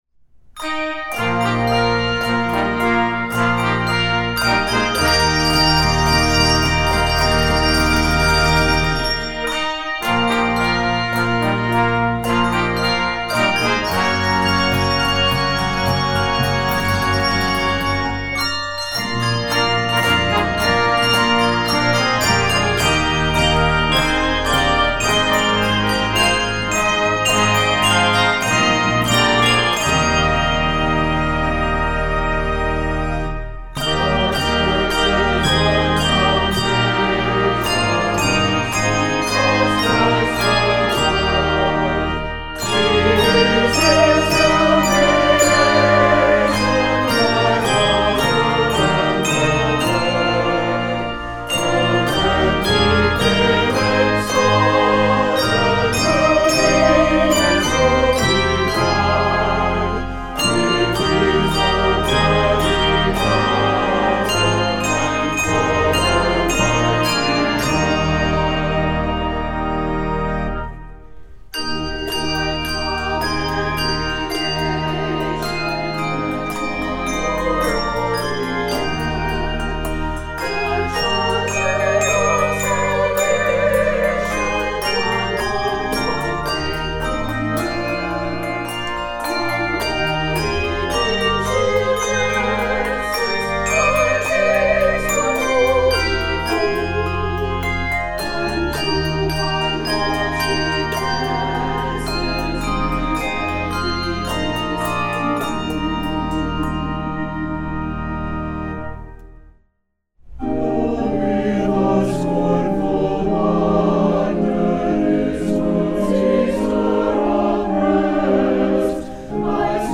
Voicing: SATB and Handbells